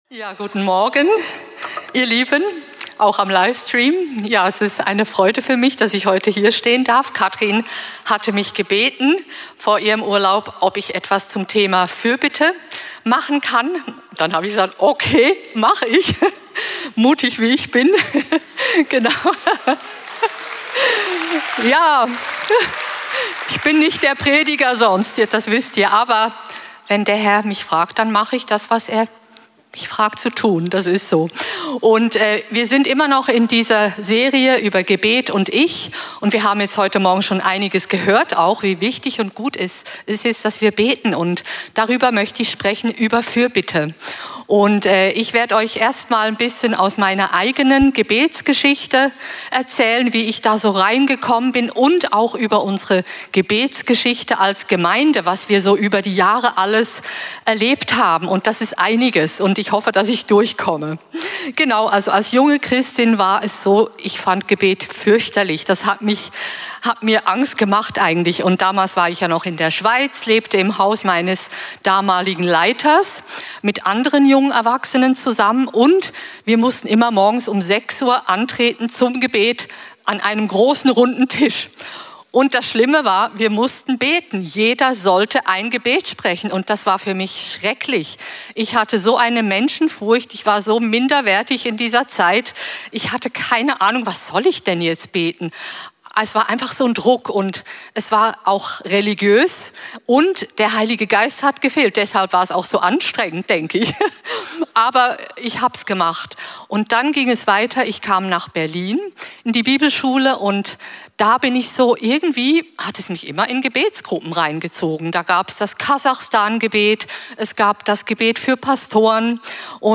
Außerdem stellen wir Predigten und Seminare zur Verfügung, die in unserer Gemeinde stattfinden.